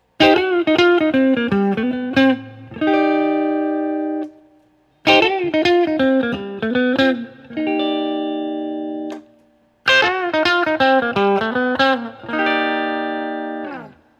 Every sound sample cycles from the neck pickup, to both, to the bridge pickup.
Stray Cat Strut Riff
[/dropshadowbox]For these recordings I used my normal Axe-FX Ultra setup through the QSC K12 speaker recorded into my trusty Olympus LS-10.